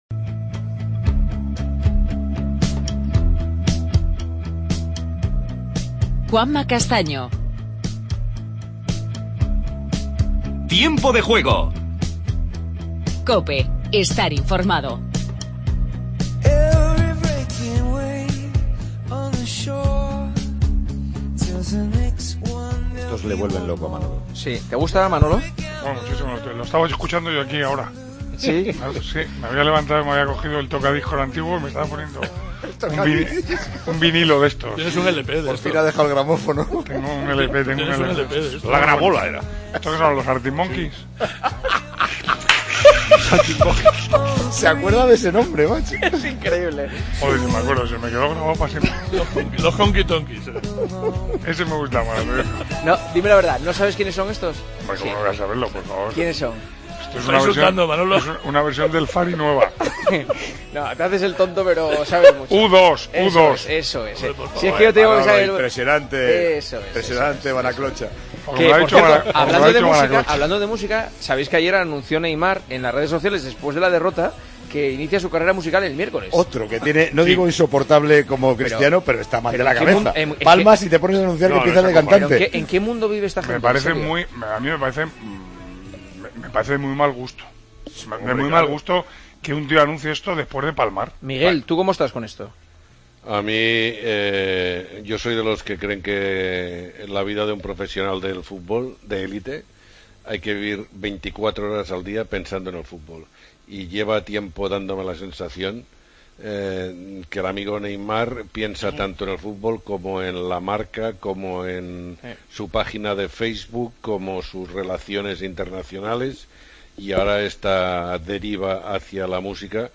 Redacción digital Madrid - Publicado el 12 sep 2016, 02:11 - Actualizado 14 mar 2023, 11:48 1 min lectura Descargar Facebook Twitter Whatsapp Telegram Enviar por email Copiar enlace Continuamos con el Tertulión analizando la derrota del Valencia. Entrevista